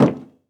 added stepping sounds
MetalSteps_04.wav